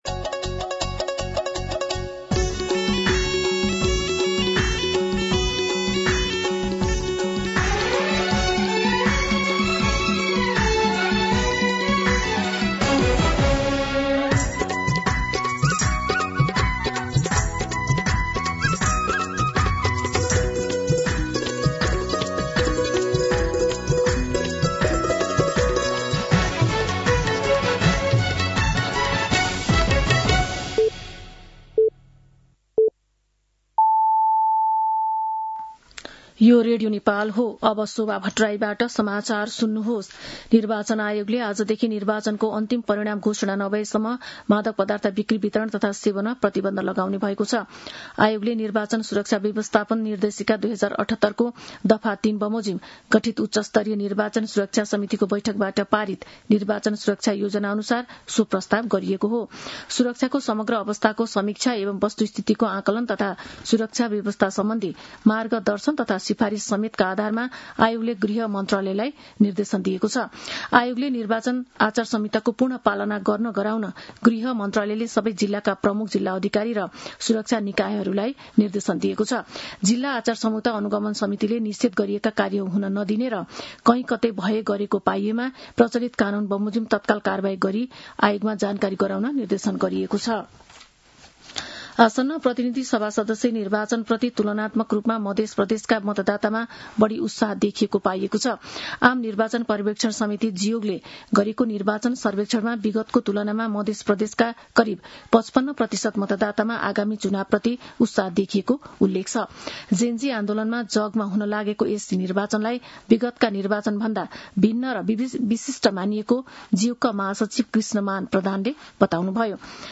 मध्यान्ह १२ बजेको नेपाली समाचार : १५ फागुन , २०८२